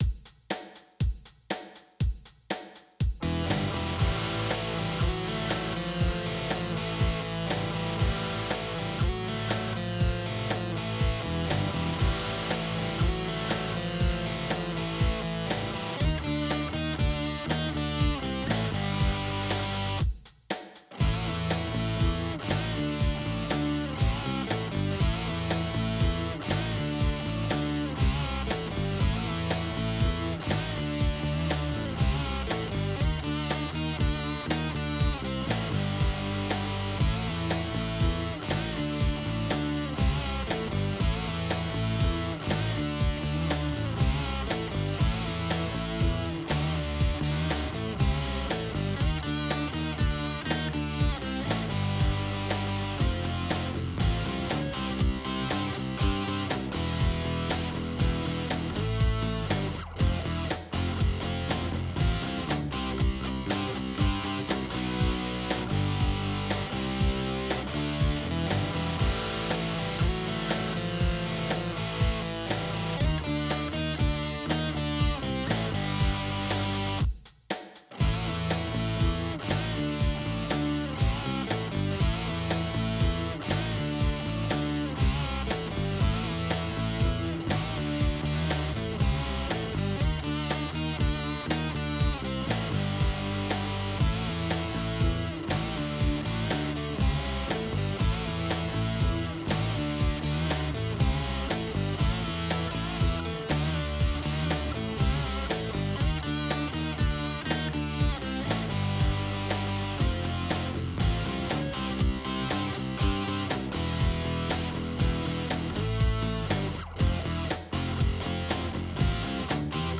OK, just re-amped the other guitar.
Attachments Git 1&2 re-amped Mix.mp3 Git 1&2 re-amped Mix.mp3 8.5 MB · Views: 116